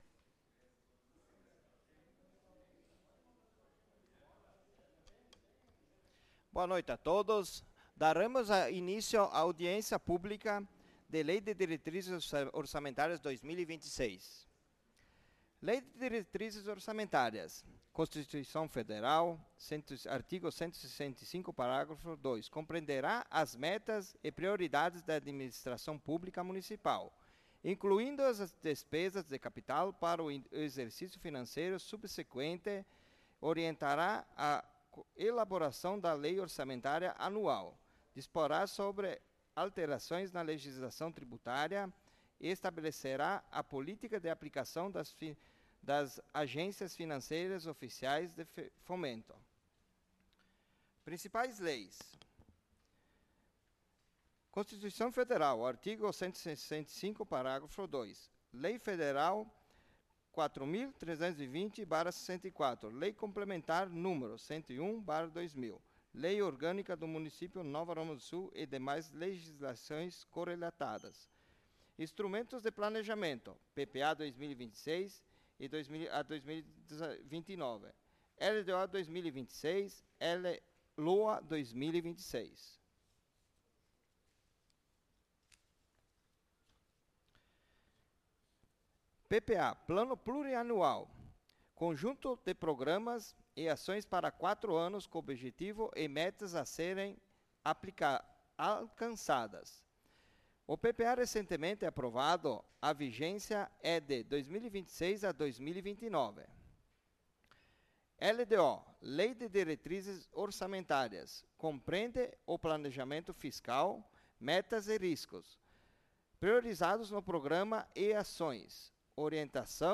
Audiencia Pública
Câmara de Vereadores de Nova Roma do Sul